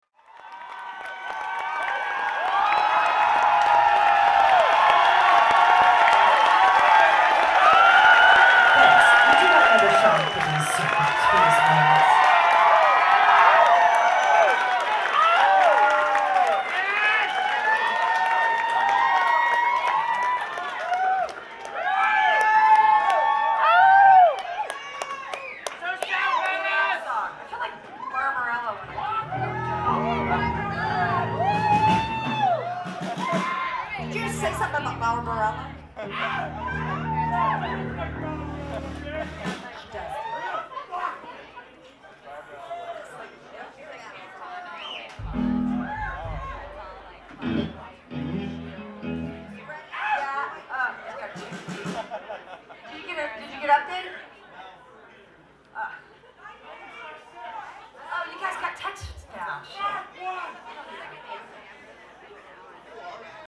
Live at the Paradise
in Boston, MA
banter
Live sets recorded with a Sony ECM-719 mic and a Sony MZ-RH10 minidisc, converted to .wav and then edited to 192kbps Mp3s.
24-Breeders-banter(live).mp3